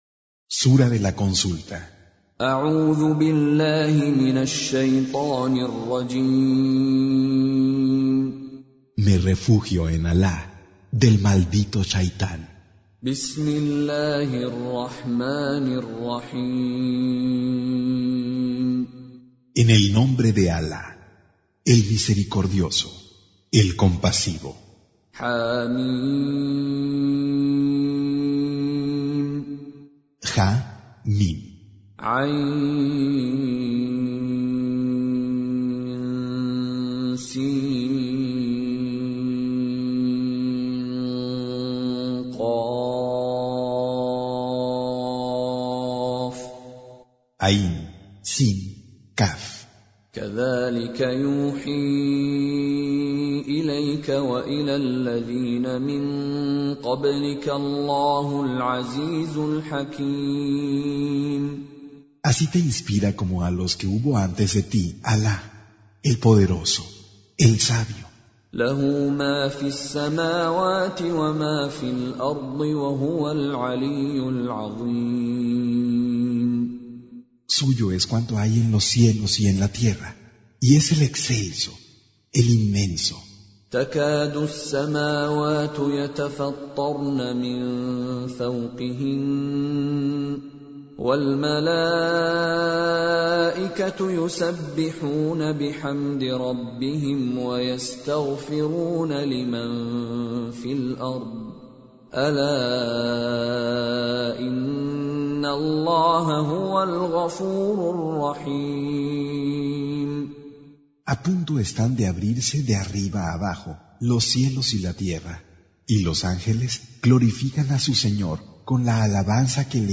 Recitation
Con Reciter Mishary Alafasi